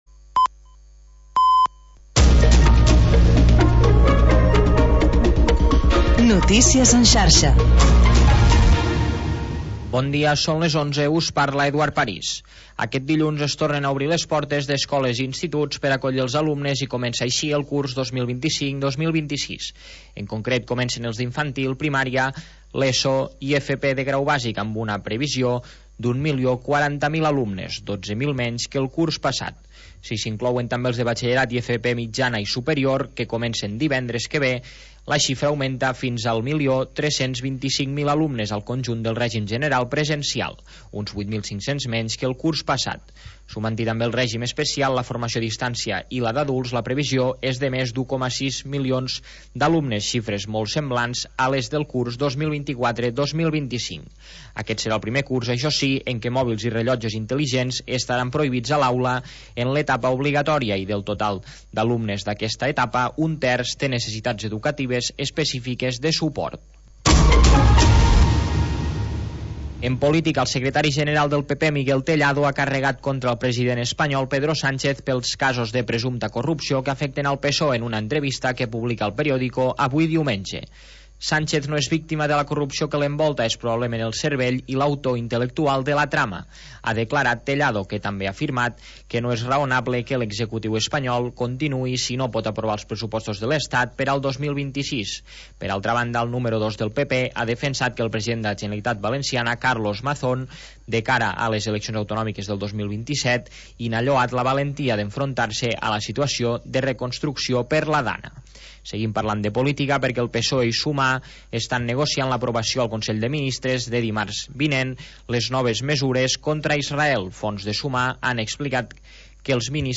Havanera, cant de taverna i cançó marinera. 15 anys obrint una finestra al mar per deixar entrar els sons més mariners